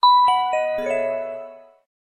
Bright.ogg